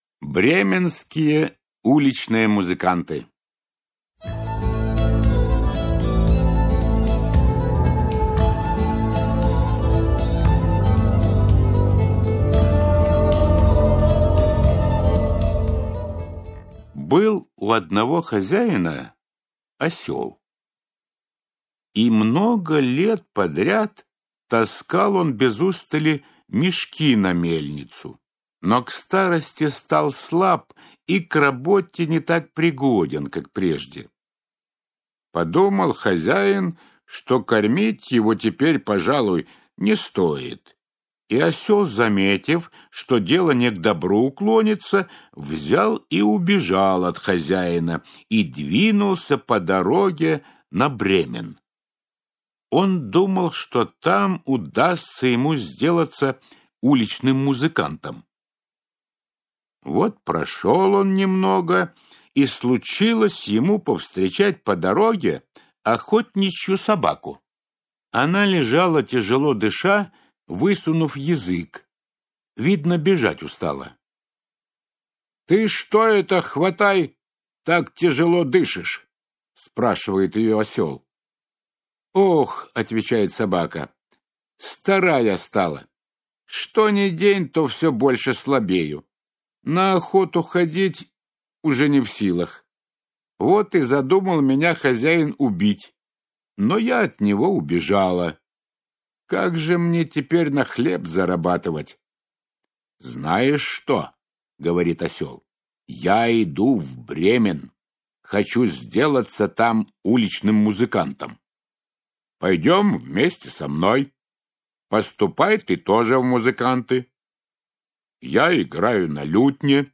Аудиокнига Лучшие сказки мира | Библиотека аудиокниг